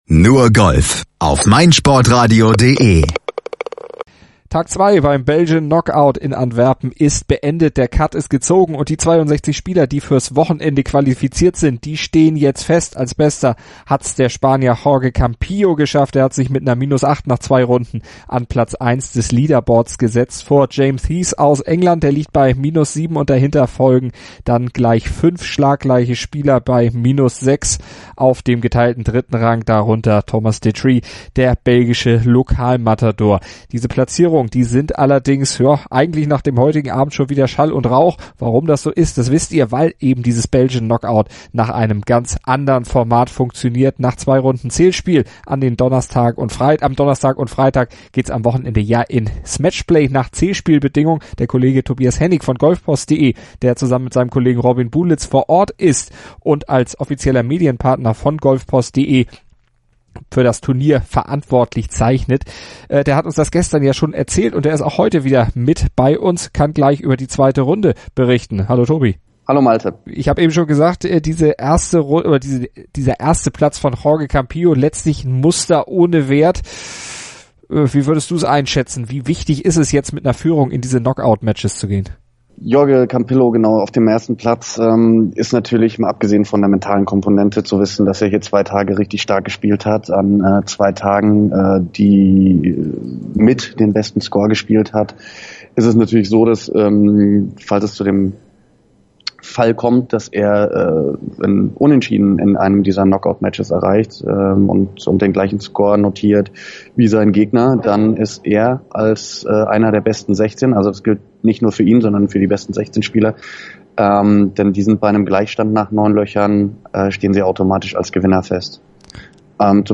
und natürlich haben sie wieder viele O-Töne der deutschen Golfer mitgebracht.